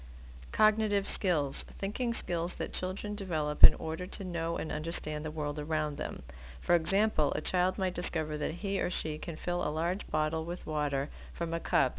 When you see the speaker speaker after each of the definitions you can hear the pronunciation of the term and what it means.